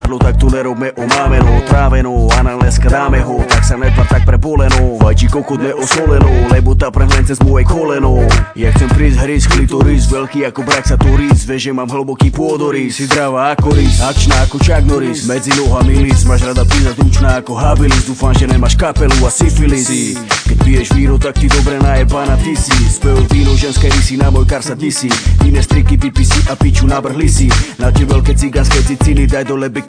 7 Styl: Hip-Hop Rok